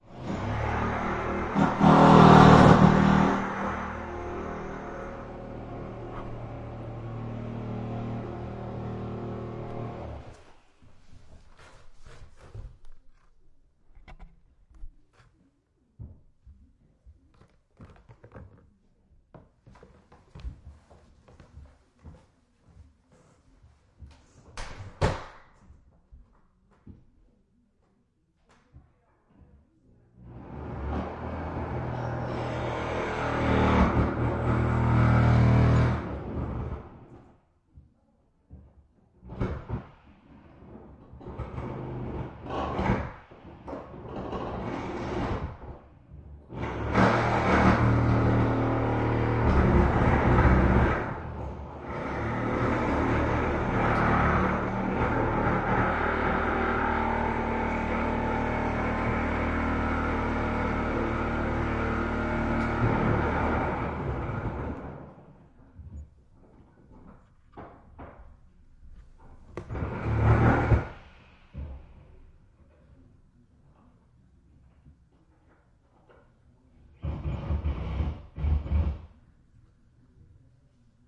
Tag: 84 bpm Hip Hop Loops Vocal Loops 3.85 MB wav Key : Unknown